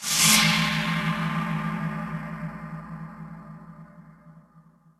Added end of skull storm sound.
skull_storm_ends.ogg